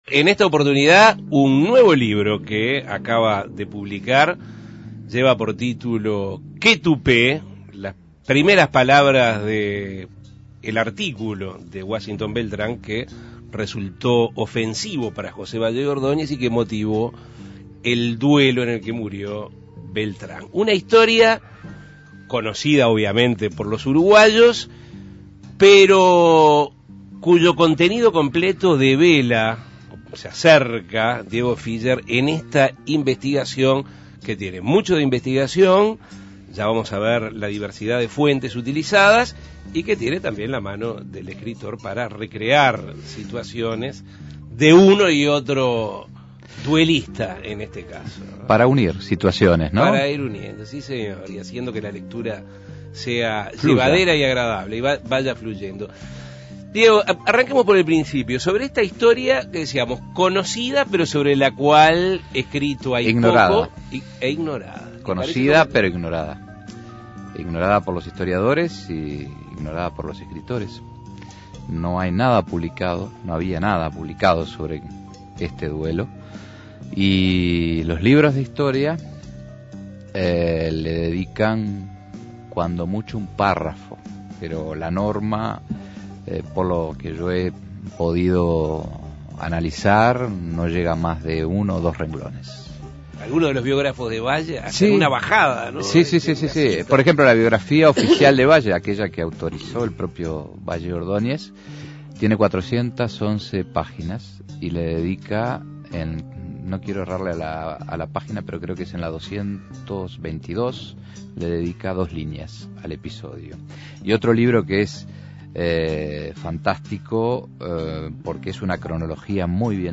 Entrevistas Qué tupé, el libro del duelo más comentado de la historia uruguaya Imprimir A- A A+ Hace 90 años, Uruguay se conmovió con la muerte de Washington Beltrán en un duelo a pistola.